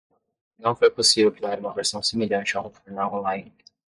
Pronounced as (IPA) /veʁˈsɐ̃w̃/